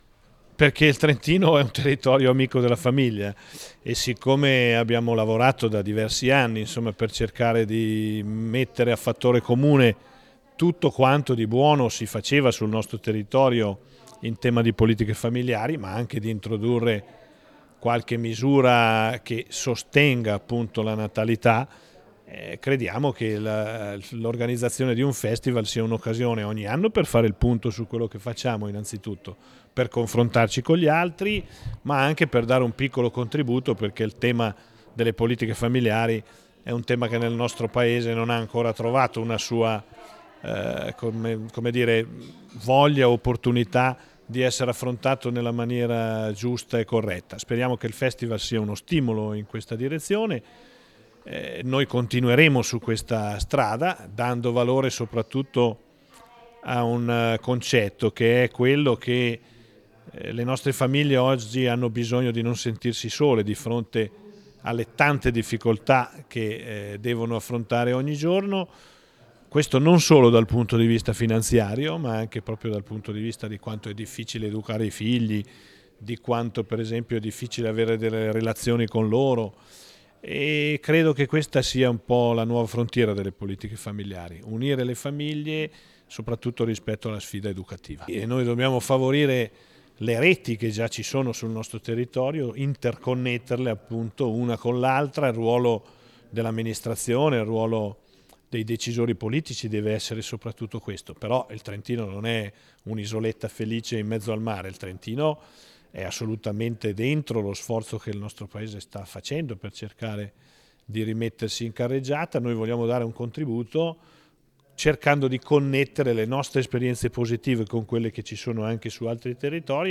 Festival della Famiglia: l'inaugurazione ufficiale al Castello del Buonconsiglio con la capo Dipartimento Siniscalchi e il presidente Rossi
Rossi_Festival_Famiglia.mp3